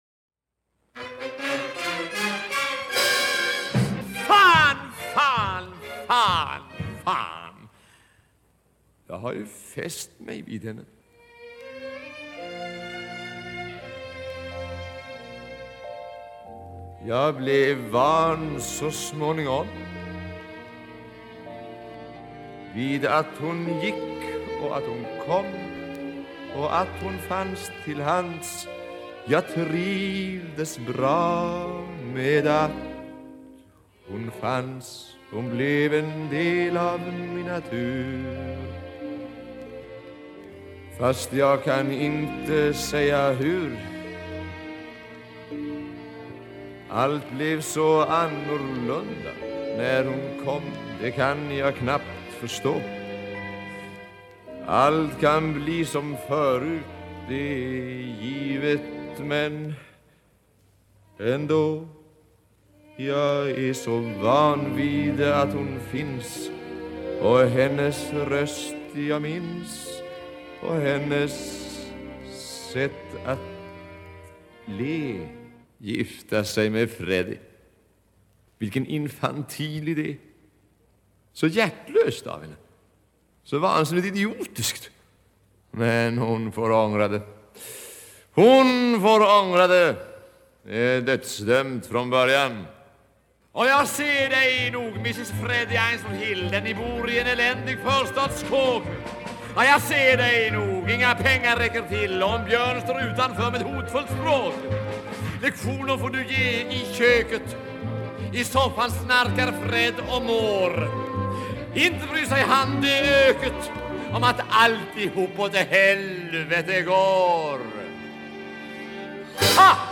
Original Swedish Cast Recording